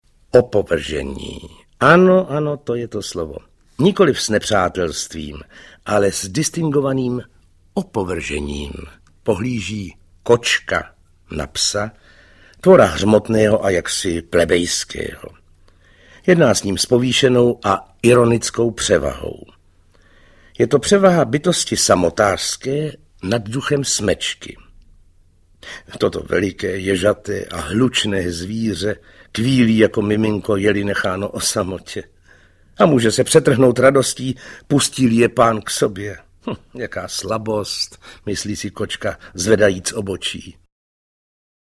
Měl jsem psa a kočku audiokniha
Mistrný projev Václava Vosky humorného popisu Čapkových trampot s přáteli člověka.
Celý popis Rok vydání 2012 Audio kniha Zkrácená verze Ukázka z knihy 99 Kč Koupit Ihned k poslechu – MP3 ke stažení Potřebujete pomoct s výběrem?